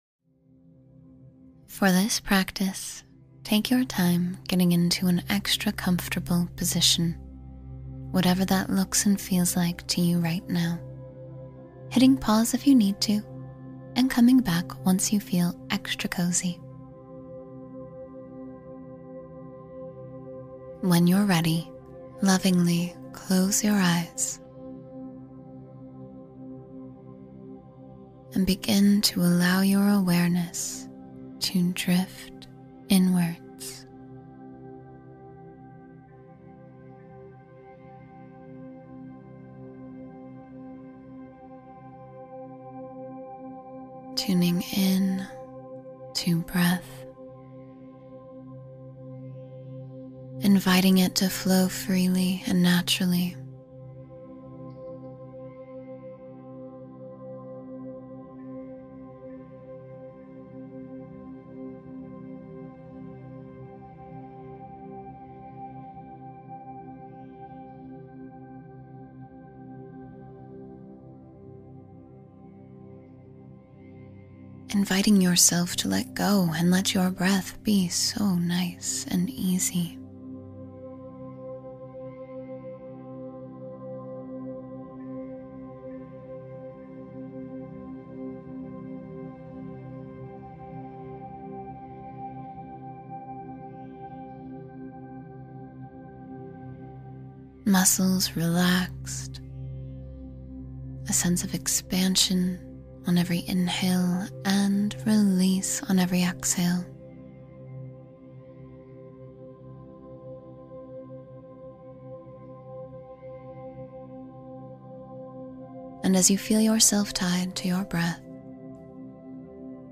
You’ll learn how to build resilience, nurture inner peace, and support your mental health through the gentle rhythm of guided breathing, visualization, and stillness.